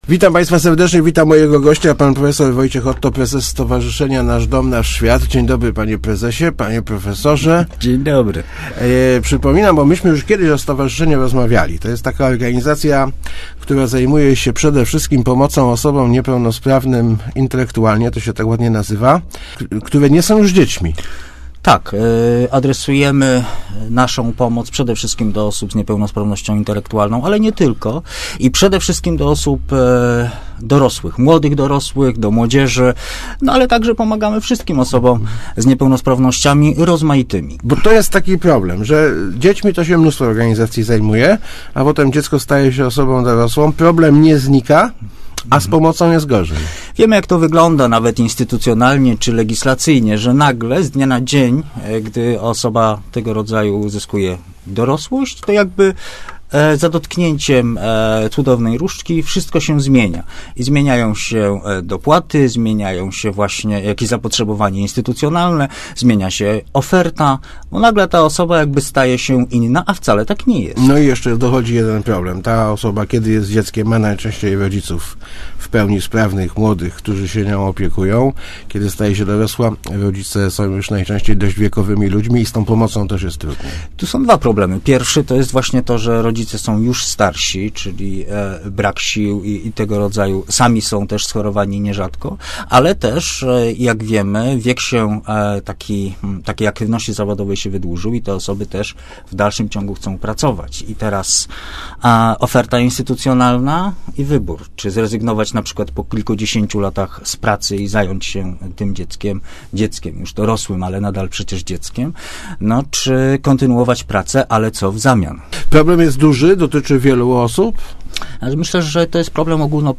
Jest tak głośno, że tego ryku słuchać się nie da.